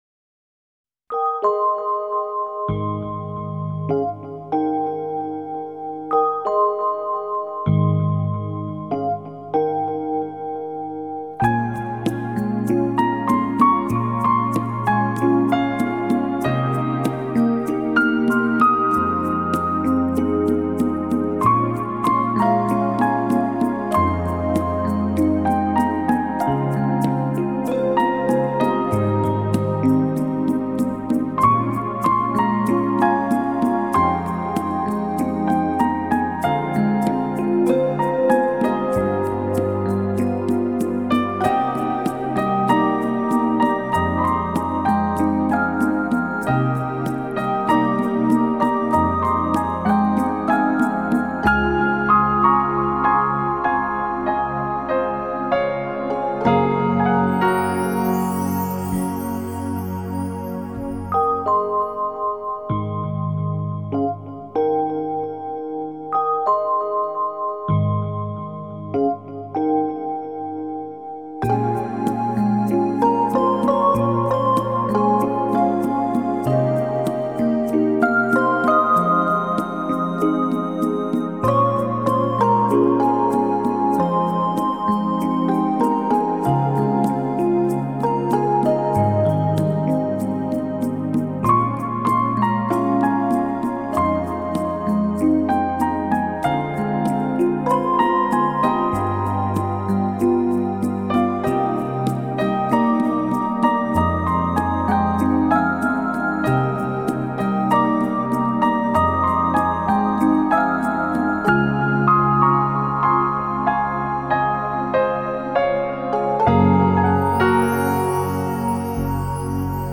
音乐流派；新世纪音乐 (New Age) / 环境音乐 (Ambient Music)
歌曲风格：轻音乐 (Easy Listening) / 纯音乐 (Pure Music)